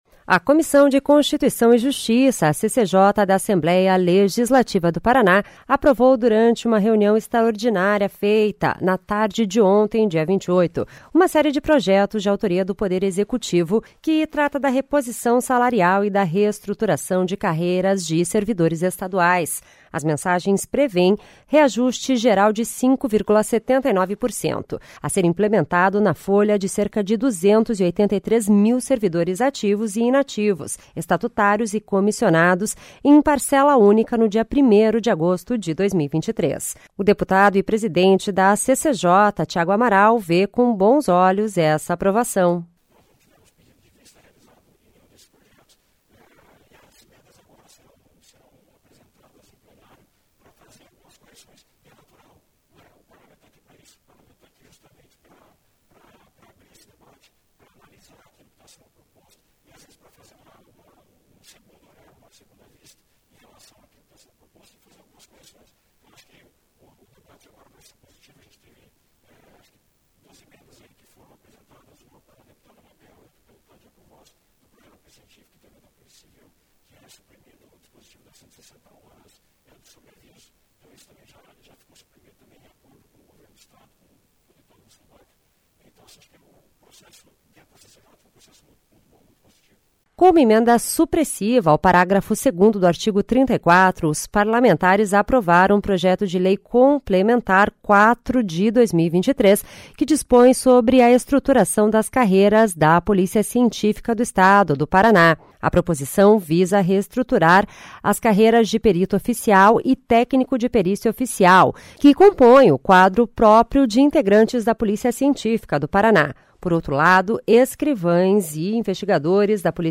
O deputado e presidente da CCJ, Tiago Amaral, vê com bons olhos essa aprovação.